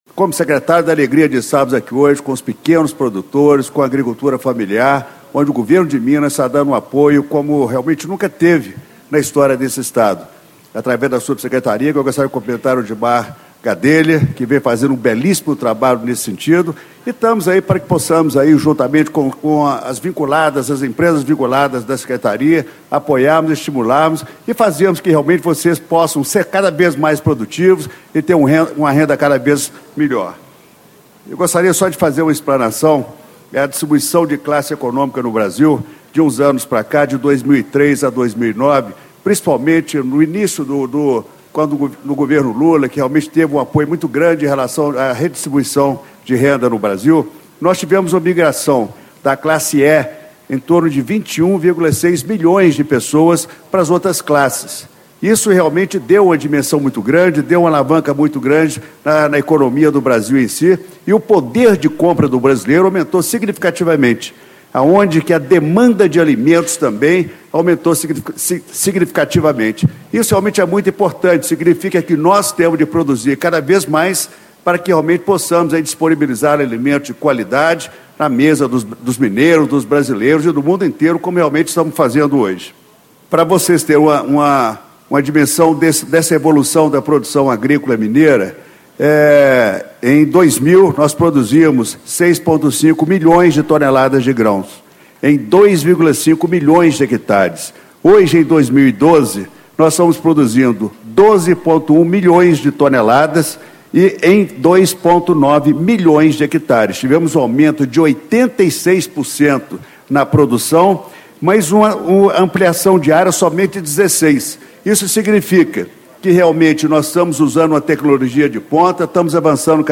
Elmiro Alves do Nascimento, Secretário de Estado de Agricultura, Pecuária e Abastecimento de Minas Gerais - Painel: Financiamento e Crédito Rural
Discursos e Palestras